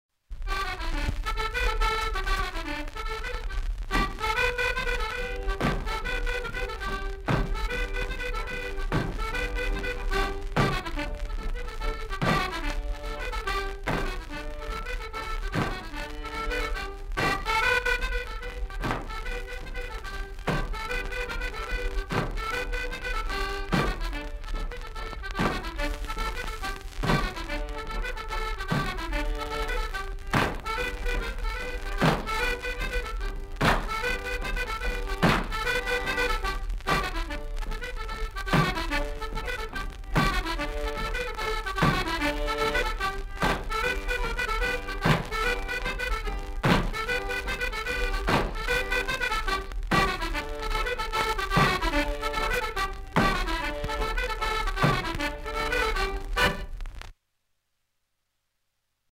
Aire culturelle : Couserans
Genre : morceau instrumental
Instrument de musique : accordéon chromatique
Danse : traversée
Notes consultables : Enregistrement d'un 45T.